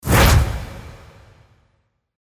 FX-841-WIPE
FX-841-WIPE.mp3